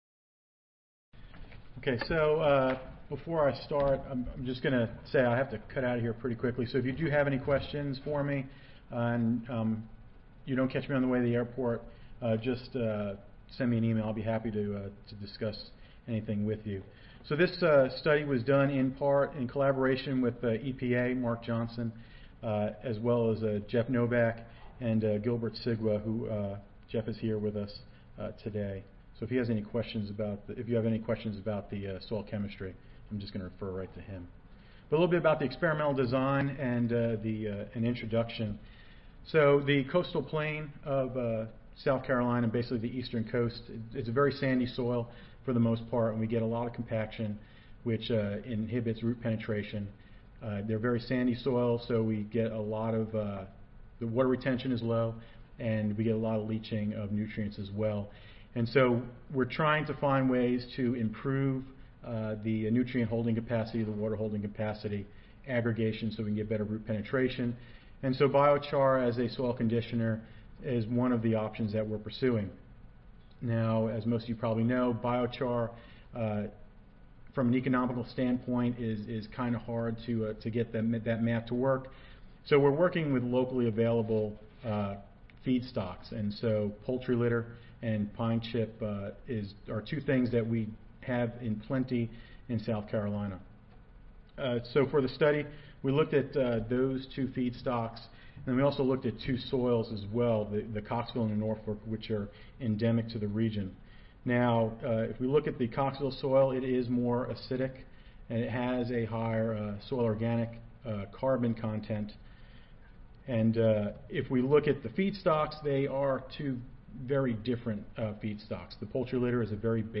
U.S. Environmental Protection Agency Audio File Recorded Presentation